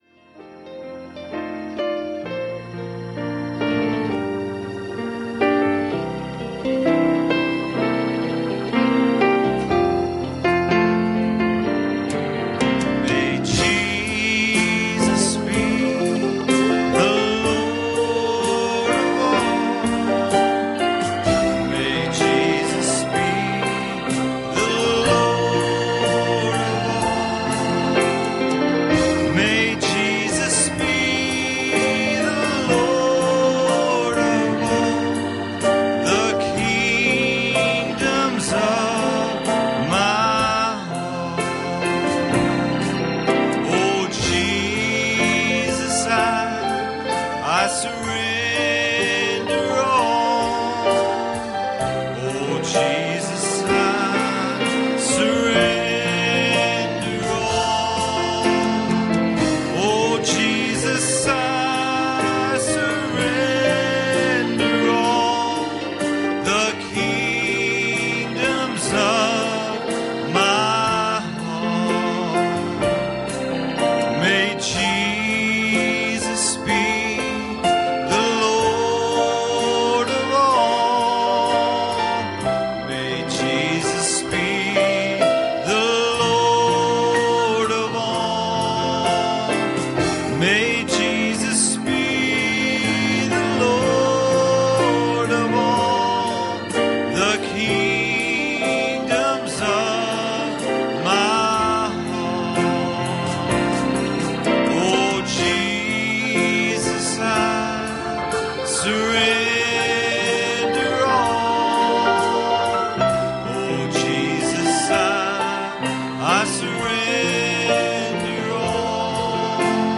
Passage: John 8:44 Service Type: Wednesday Evening